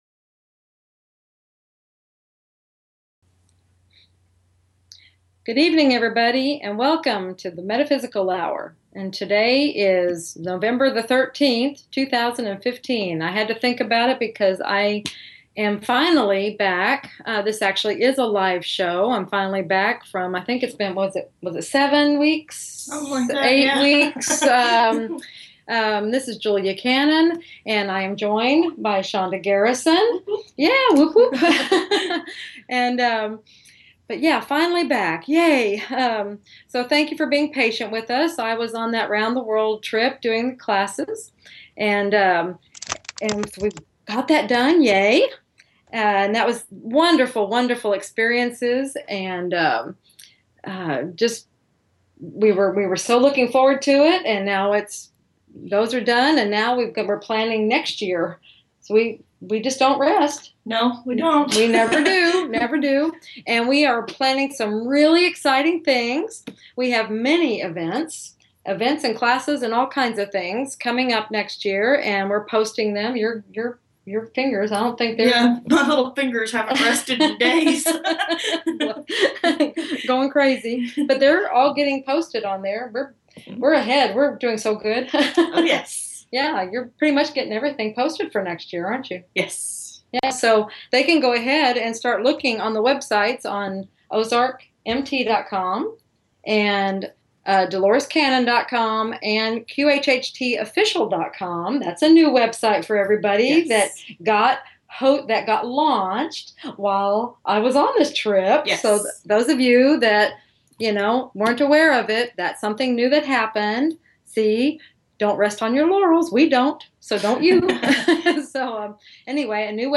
Interview
Talk Show